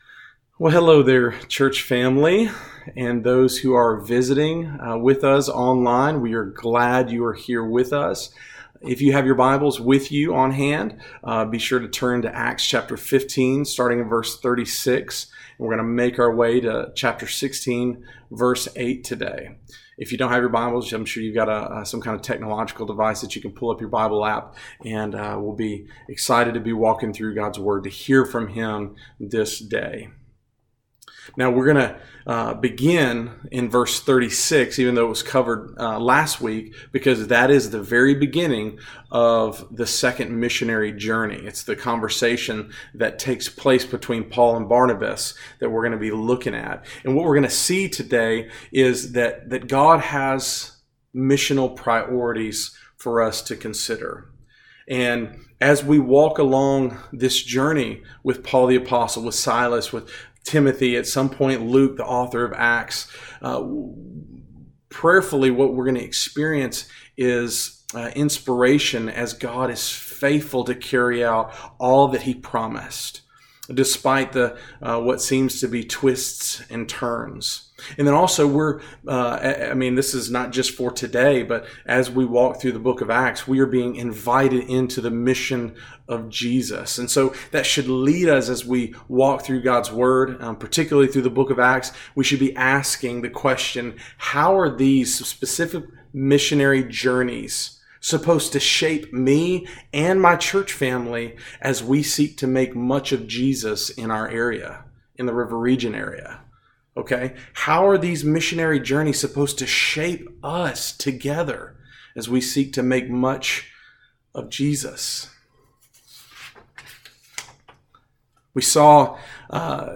Acts 16:6-40 Service Type: Sunday